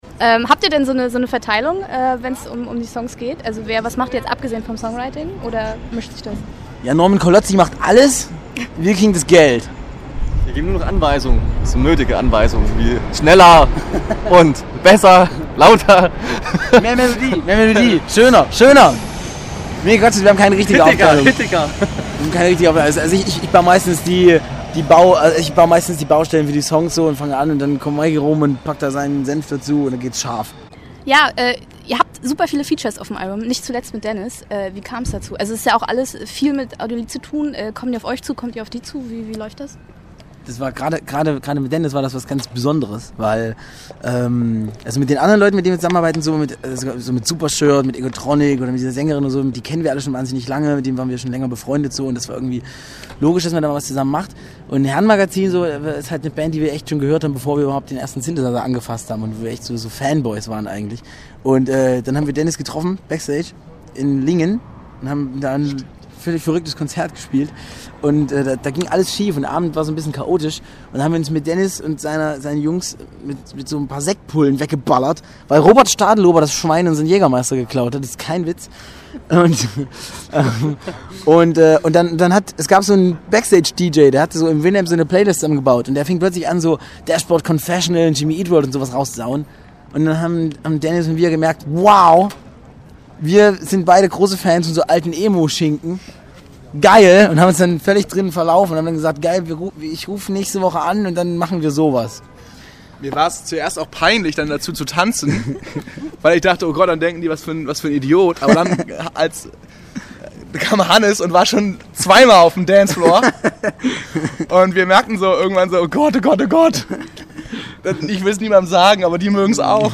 Captain Capa Interview Teil 2:
captain-capa-interview-blog-pt-2.mp3